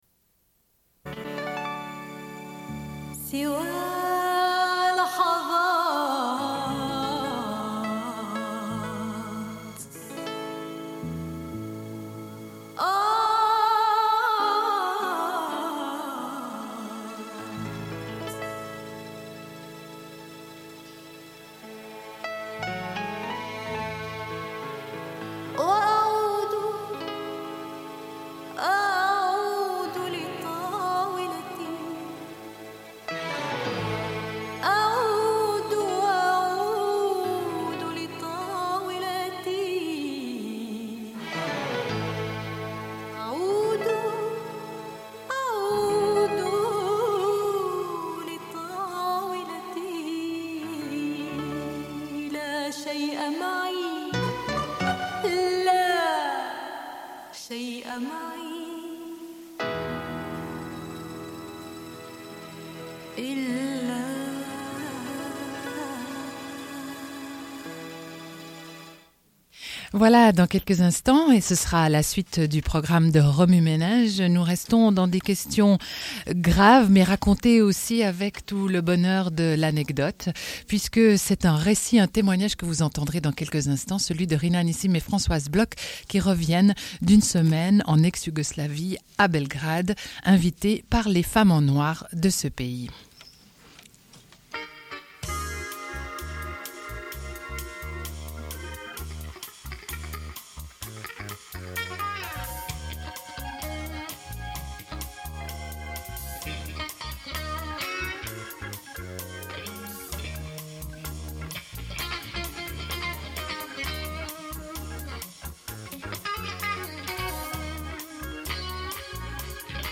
Une cassette audio, face A31:26